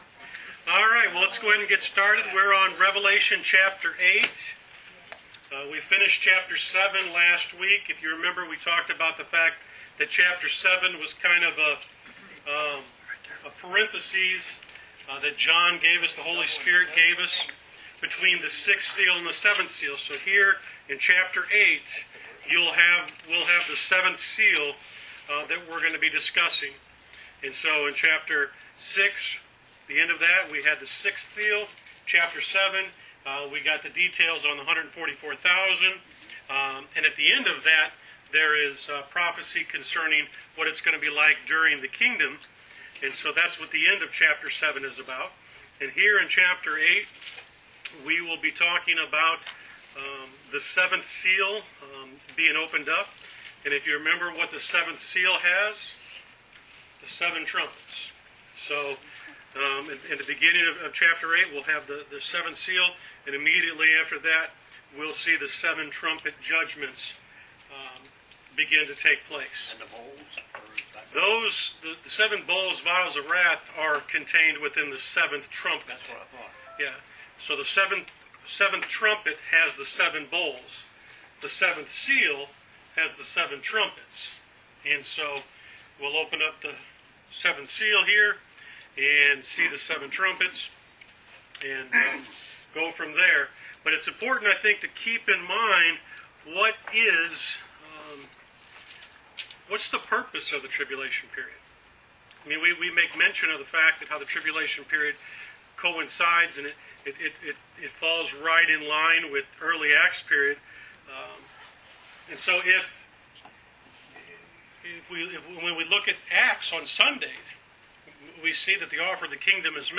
Wednesday Bible Study: Rev Ch 8 Pt 2